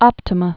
(ŏptə-mə)